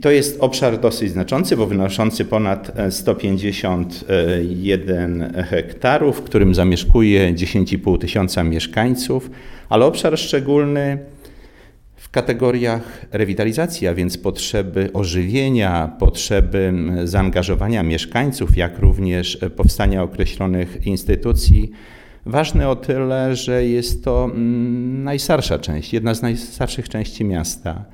Zdzisław Przełomiec, przewodniczący Rady Miasta Suwałki zwraca uwagę, że problem jest poważny, bo dotyczy 15 procent mieszkańców.